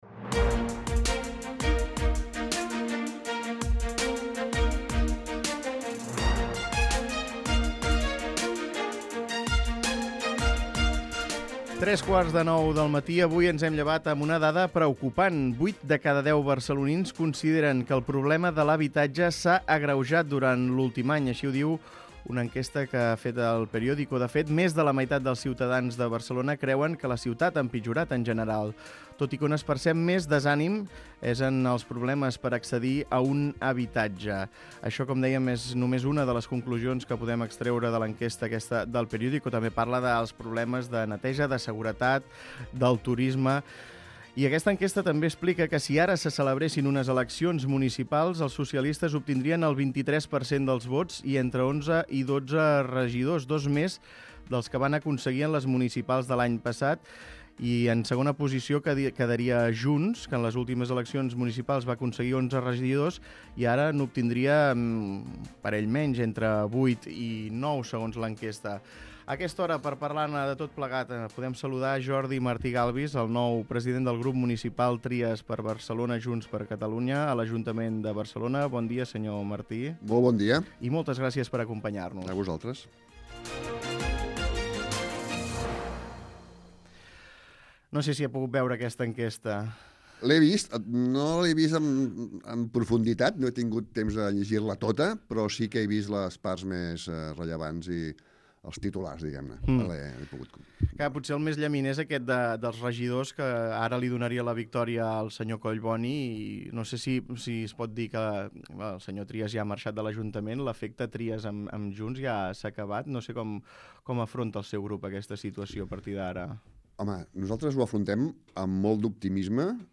Escolta l'entrevista a Jordi Martí Galbis, president del grup Trias-Junts a l'Ajuntament de Barcelona